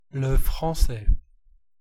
Tiếng Pháp (le français, IPA: [lə fʁɑ̃sɛ]
Fr-le_français-fr-ouest.ogg.mp3